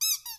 quack.mp3